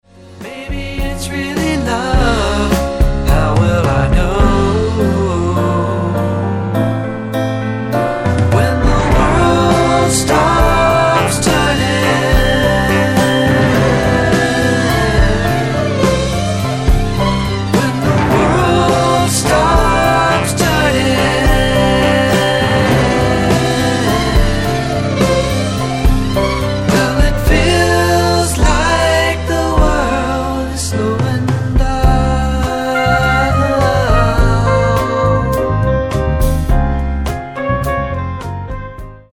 壮大な名曲